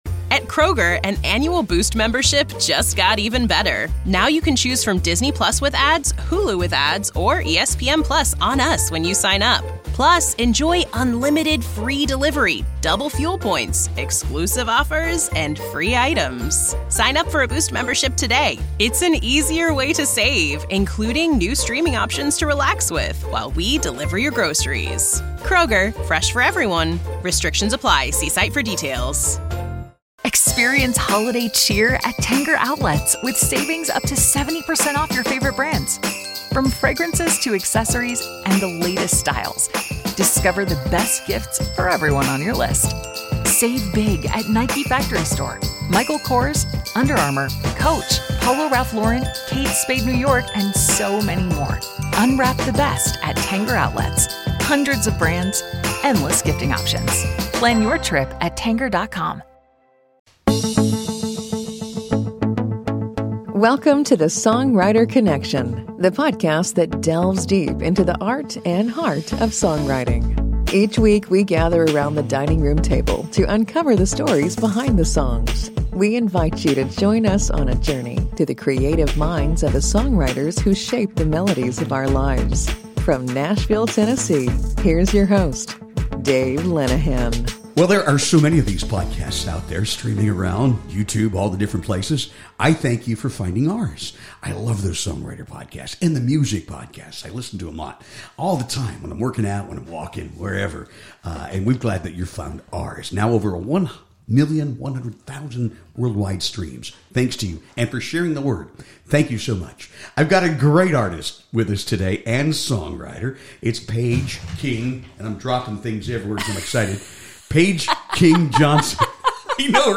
Whether you’re a budding songwriter or a country music fan, you won’t want to miss this heartfelt conversation!